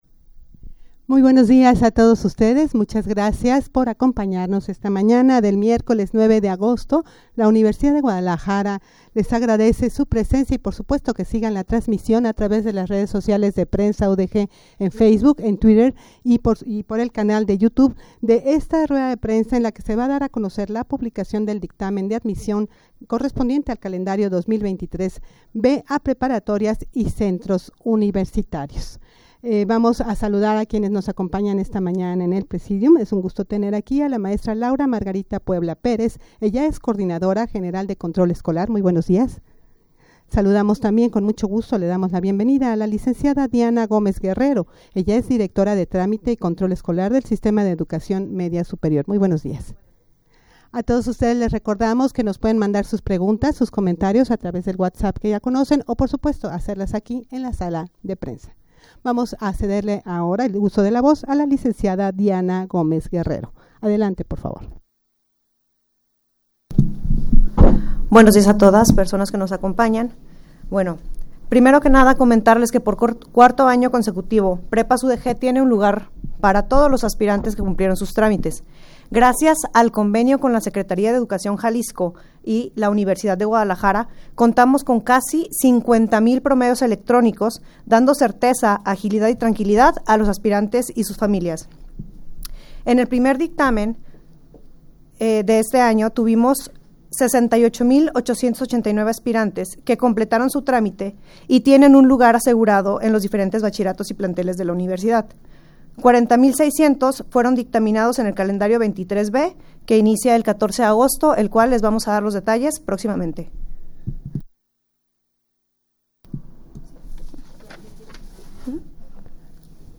Audio de la Rueda de Prensa
rueda-de-prensa-en-la-que-se-dara-a-conocer-la-publicacion-del-dictamen-de-admision-calendario-2023-b.mp3